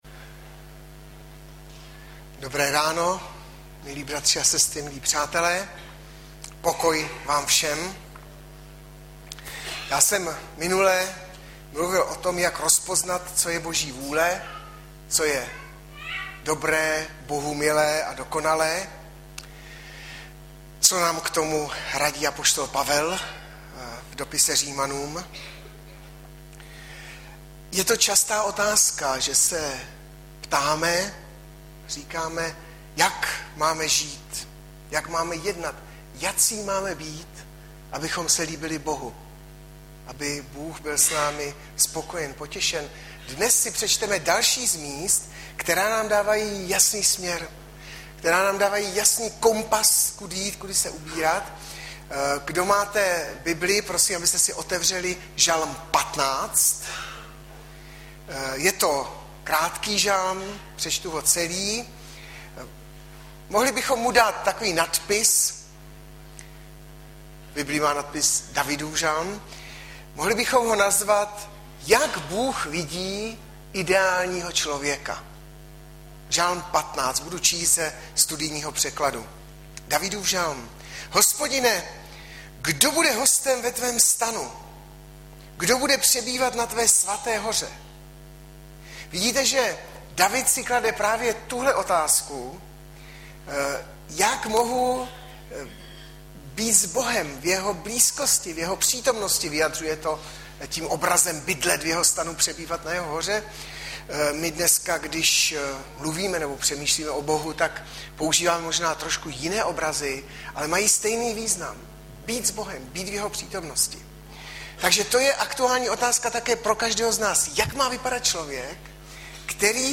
Hlavní nabídka Kázání Chvály Kalendář Knihovna Kontakt Pro přihlášené O nás Partneři Zpravodaj Přihlásit se Zavřít Jméno Heslo Pamatuj si mě  18.11.2012 - POMLUVY A KLEVETY - Ž 15 Audiozáznam kázání si můžete také uložit do PC na tomto odkazu.